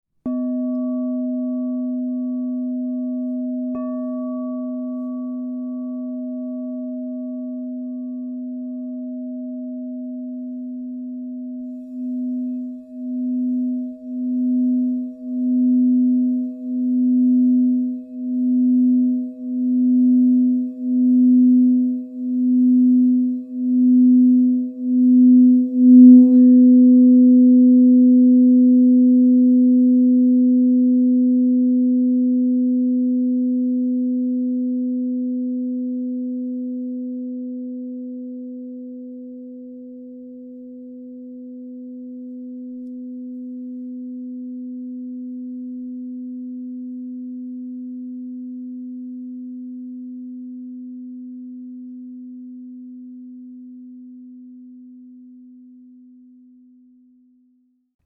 Charcoal, Palladium W/Etched Flower Of Life Globe 10" B +25 Crystal Tones singing bowl
The expansive 10-inch size delivers rich, resonant tones, making it perfect for group sound healing sessions, personal practice, or enhancing sacred spaces.
Experience this 10″ Crystal Tones® alchemy singing bowl made with Charcoal, Palladium w/etched Flower Of Life Globe in the key of B +25.
528Hz (+)